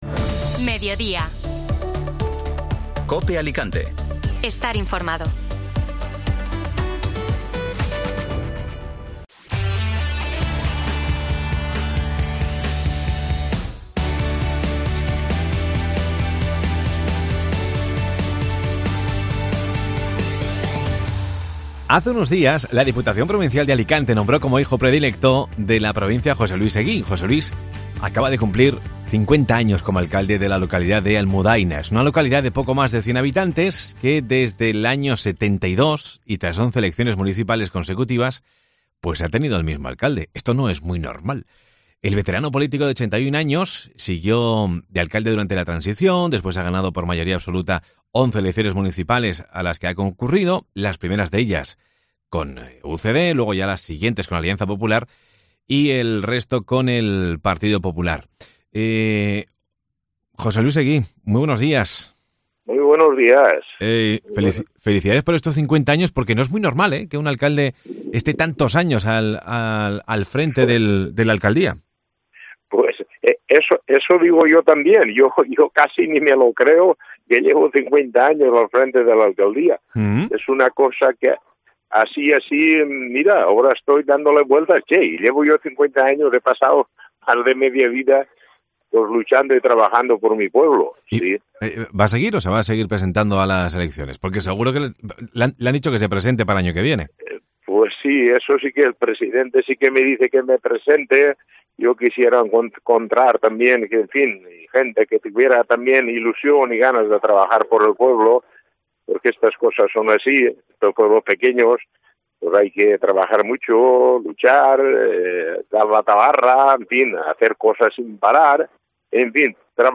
Escucha la entrevista en Mediodía COPE Alicante